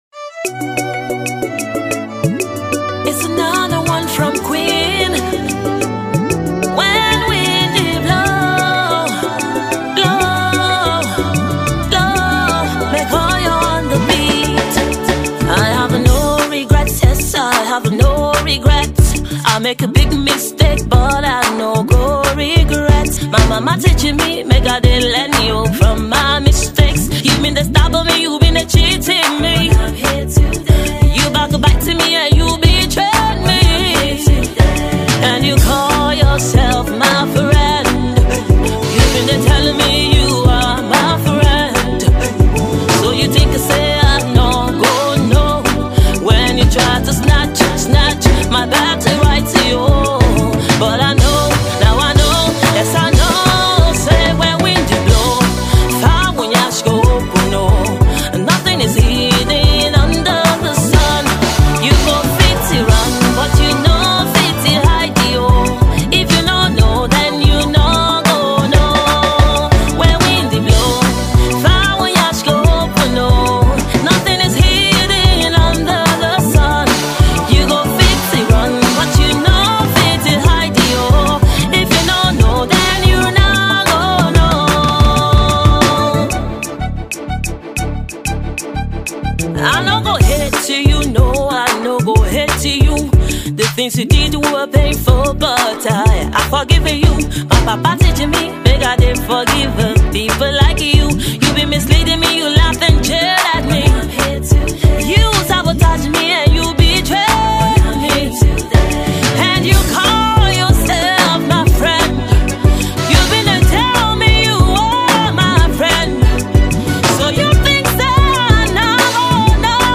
atmospheric and ethereal sounds of Africa